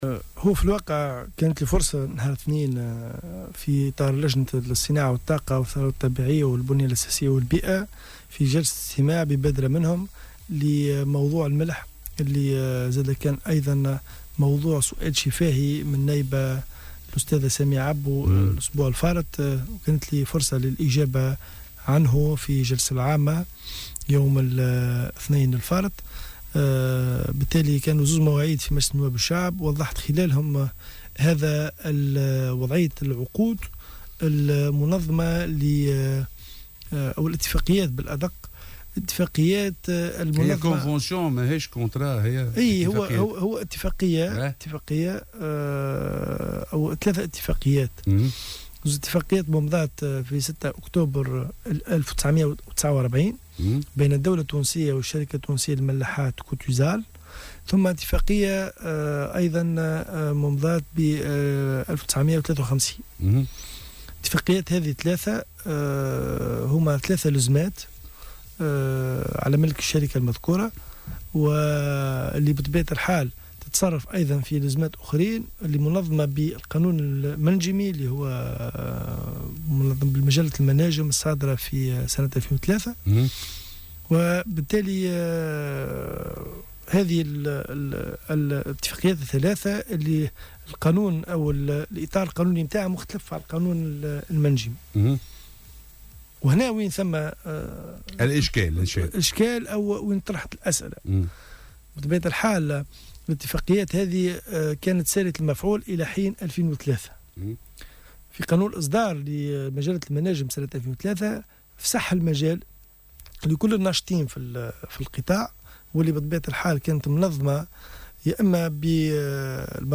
Le secrétaire d’Etat aux mines, Hachem Hmidi, a annoncé ce mercredi 30 mai 2018 sur les ondes de Jawhara FM que le gouvernement envisage sérieusement la résiliation du contrat de concession qui lie l’Etat tunisien à la société Cotusal pour l’exploitation du sel naturel.